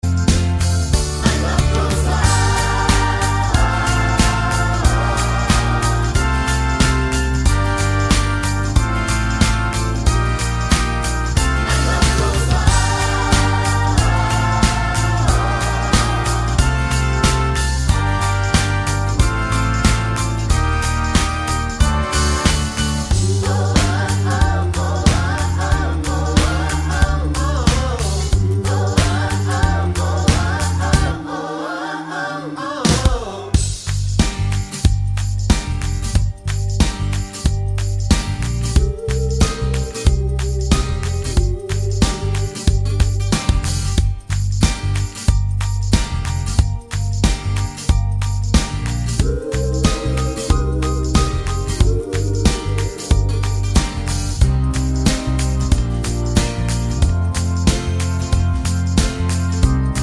no Backing Vocals Duets 3:42 Buy £1.50